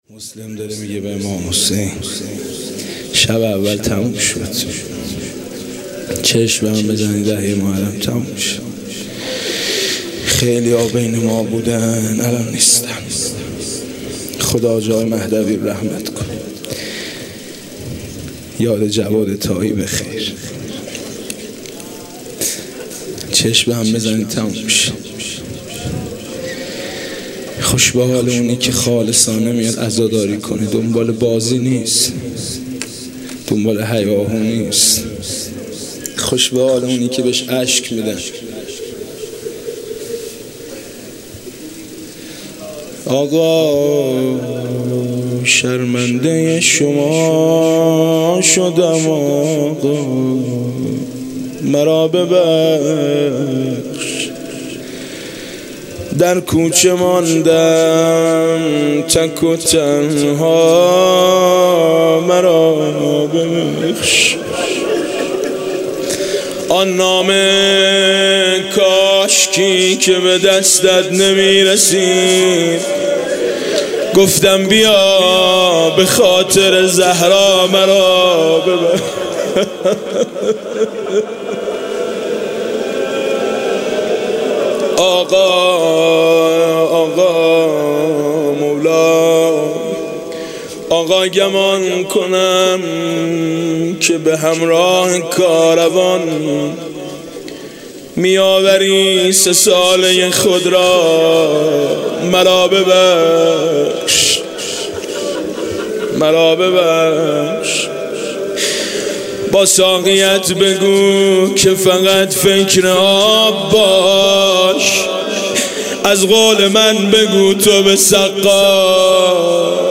گروه معارف - رجانیوز: مراسم عزاداری و سوگواری سرور و سالار شهیدان حضرت اباعبدالله الحسین(ع) در  محرم ۱۴۳۷ هـ.ق با سخنرانی حجت الاسلام والمسلمین پناهیان و مداحی حاج میثم مطیعی به مدت ۱۱ شب از ۲۲ مهر بعد از نماز مغرب و عشاء در هیئت میثاق با شهدا واقع در بزرگراه چمران، پل مدیریت، دانشگاه امام صادق(ع) برگزار می‌شود.
صوت مراسم شب اول محرم ۱۴۳۷ هیئت میثاق با شهدا ذیلاً می‌آید: